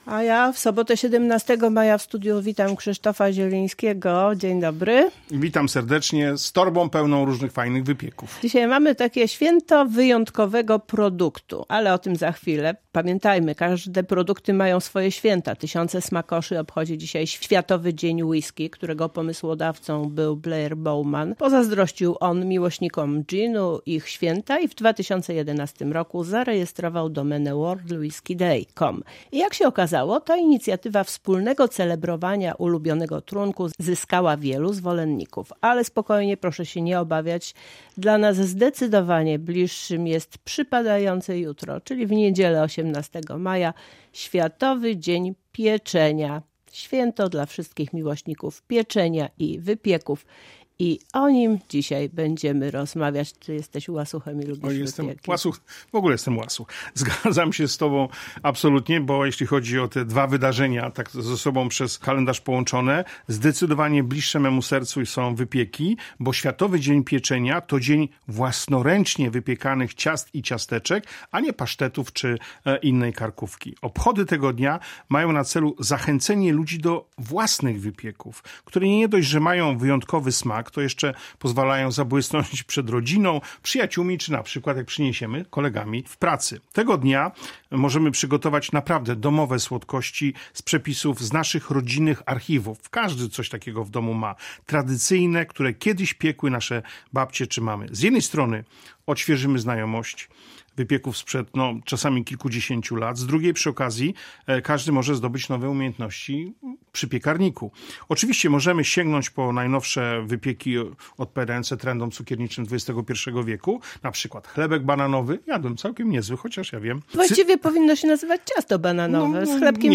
O urokach rodzinnych receptur i znaczeniu domowego pieczenia rozmawiają